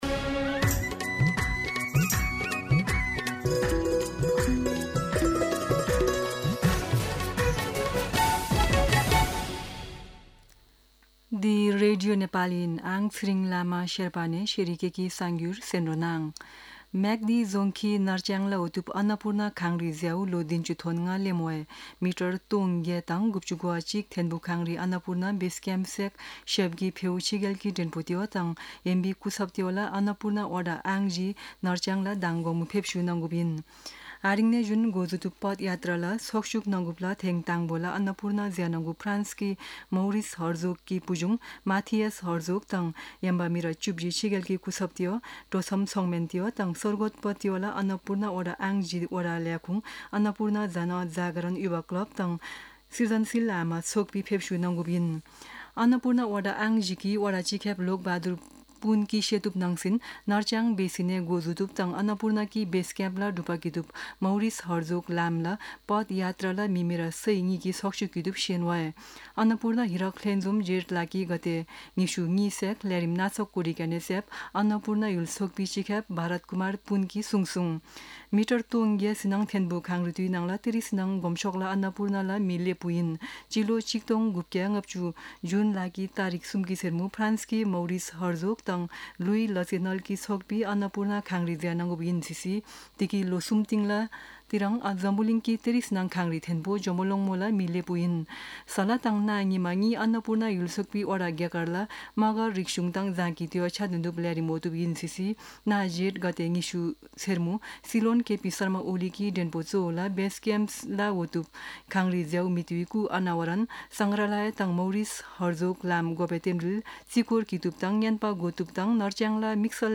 शेर्पा भाषाको समाचार : १८ जेठ , २०८२
Sherpa-News-18.mp3